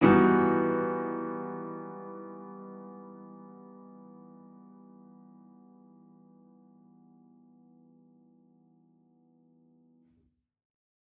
Index of /musicradar/gangster-sting-samples/Chord Hits/Piano
GS_PiChrd-Emin9maj7.wav